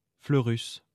来自 Lingua Libre 项目的发音音频文件。 语言 InfoField 法语 拼写 InfoField Fleurus 日期 2021年12月29日 来源 自己的作品